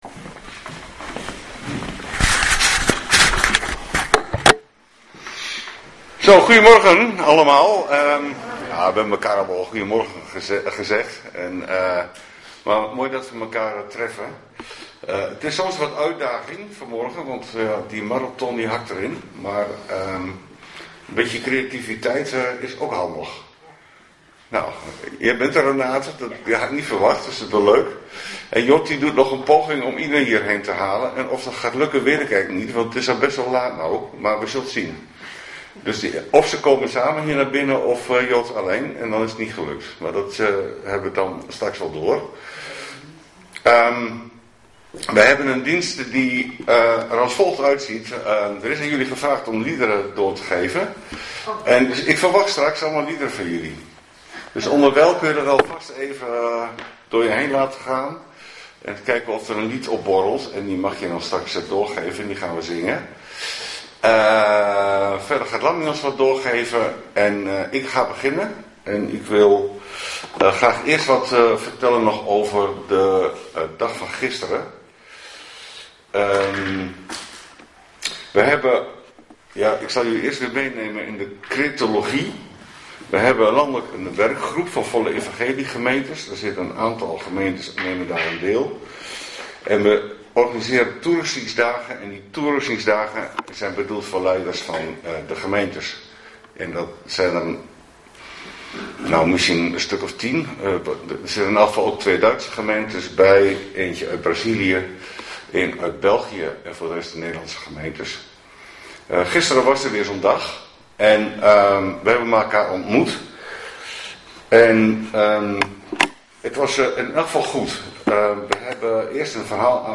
12 april 2026 dienst - Volle Evangelie Gemeente